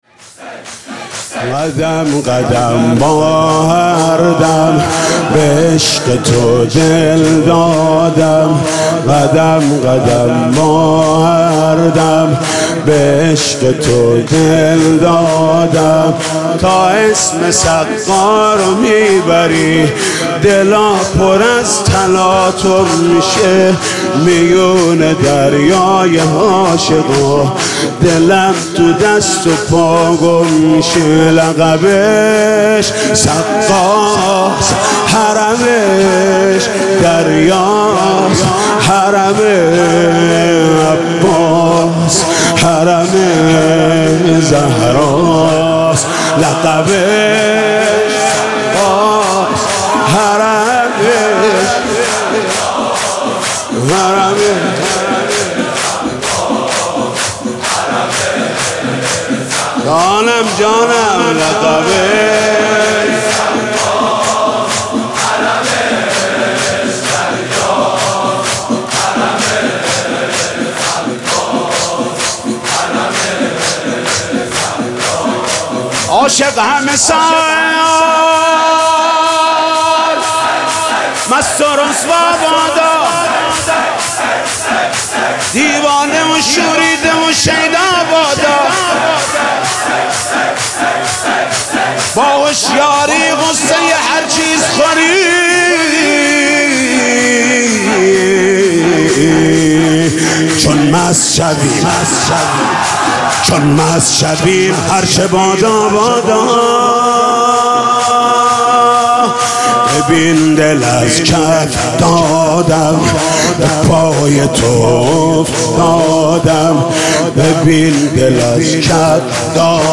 «فاطمیه 1396» شور: قدم قدم با هر دم، به عشق تو دل دادم